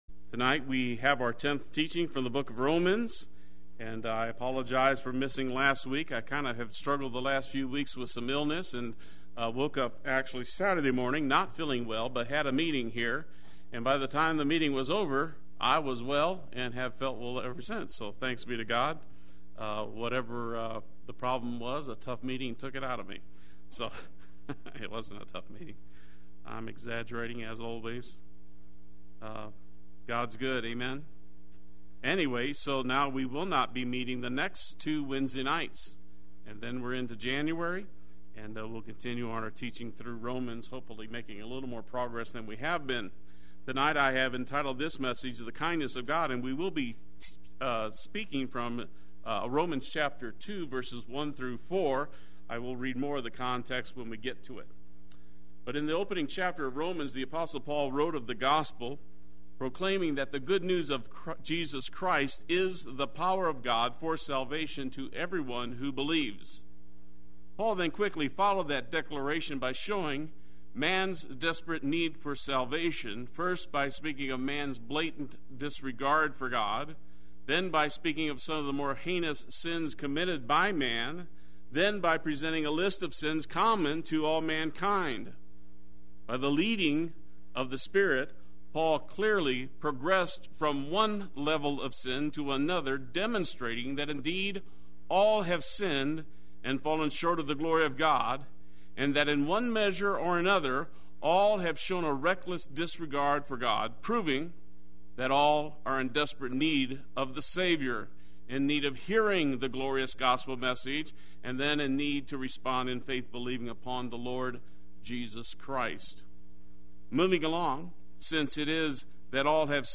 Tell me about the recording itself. The Kindness of God Wednesday Worship